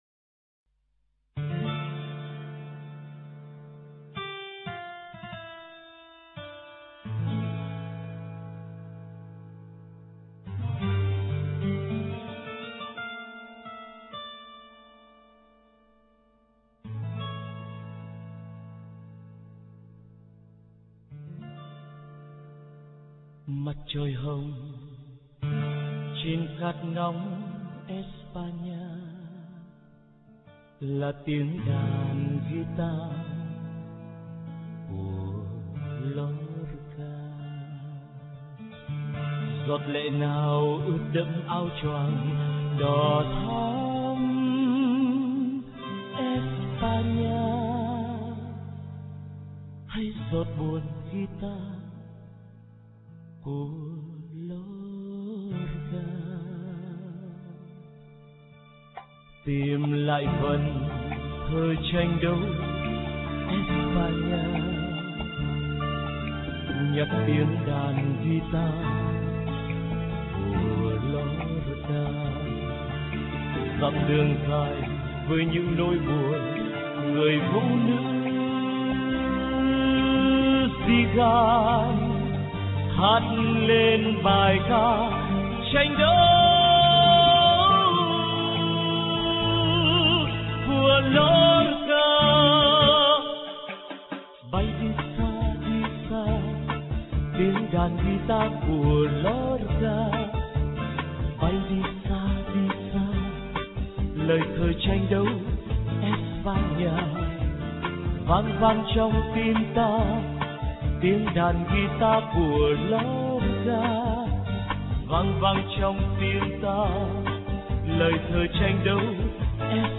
Sách nói | Đàn ghi ta của Lo-ca